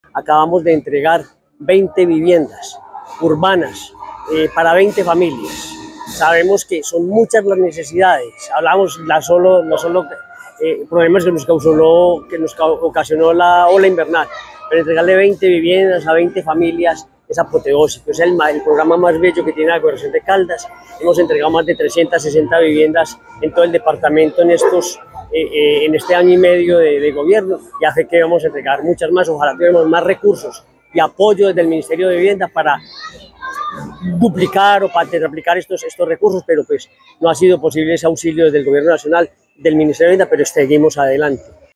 Henry Gutiérrez Ángel, gobernador de Caldas.